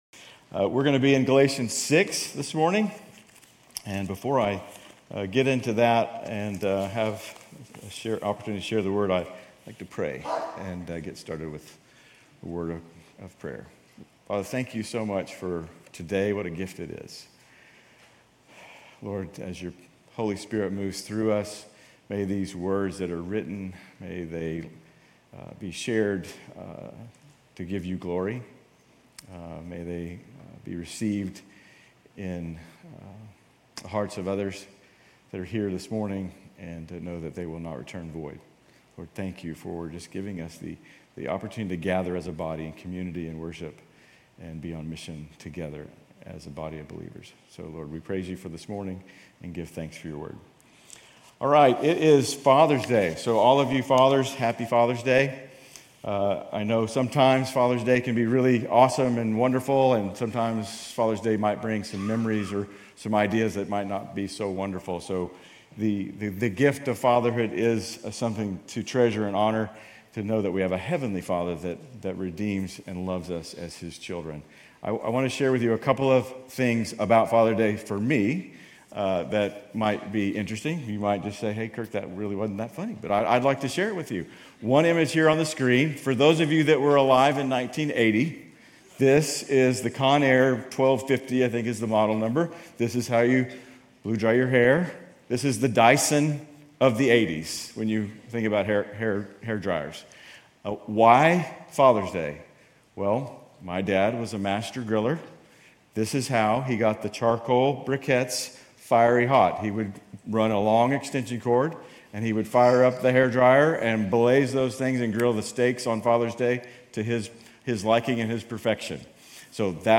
Grace Community Church University Blvd Campus Sermons Galatians 6:11-18 Jun 17 2024 | 00:27:49 1x 00:00 / 00:27:49 Subscribe Share RSS Feed Share Link Embed